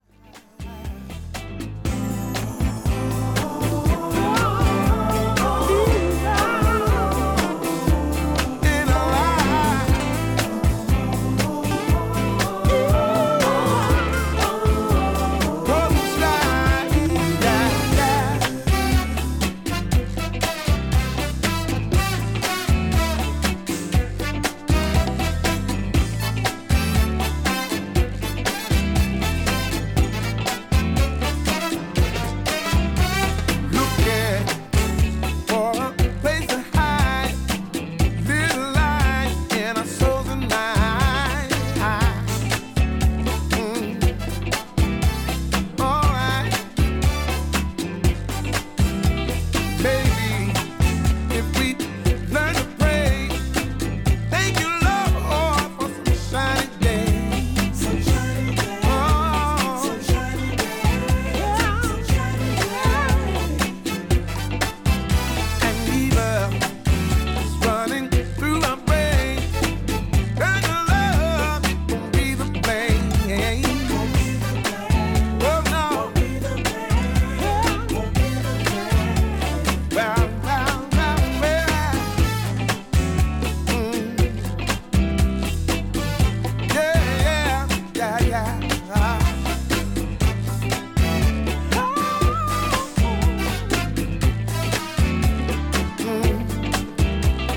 STYLE Soul